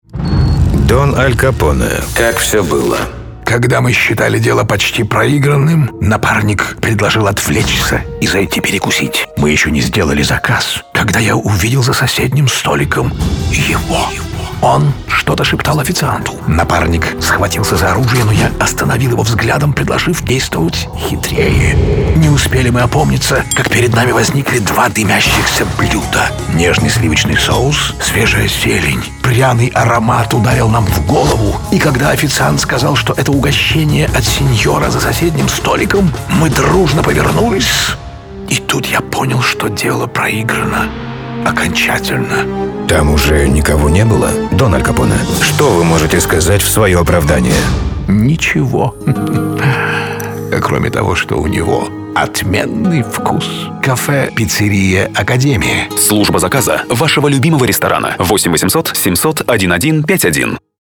Популярный актер, режиссер, диктор. Официальный голос Аль Пачино в российском дубляже.